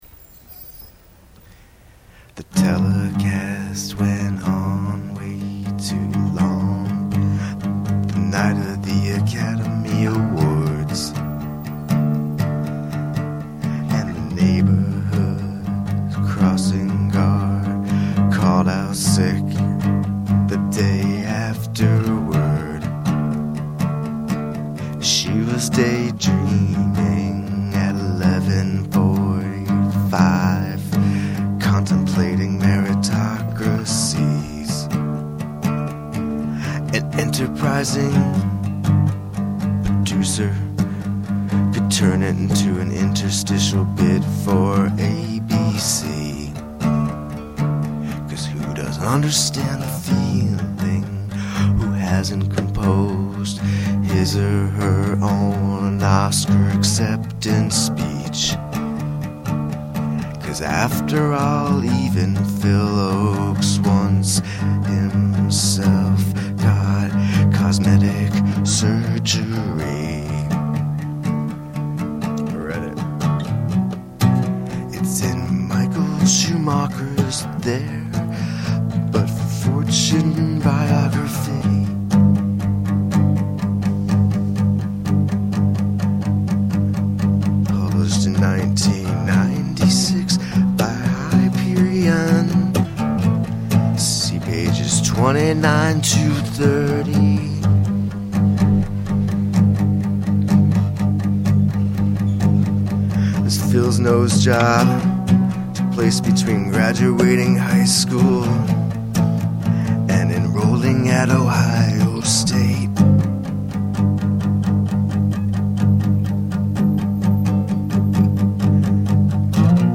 (Acoustic Demo)